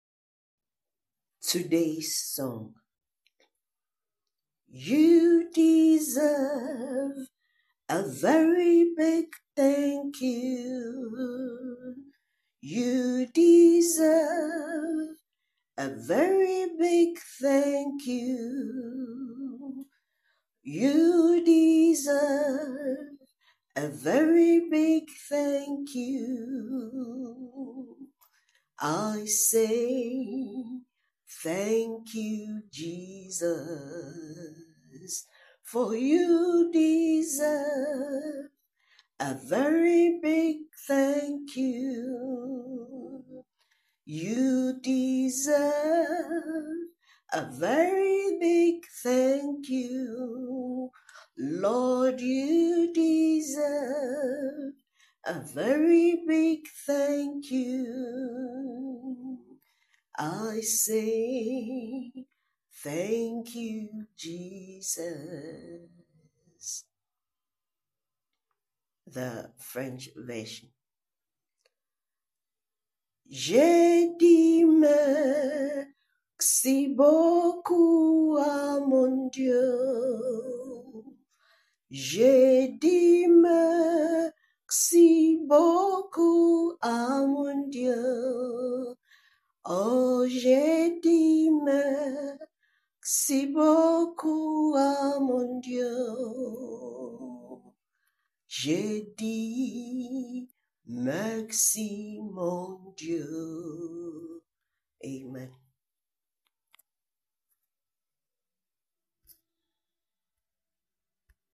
Song for meditation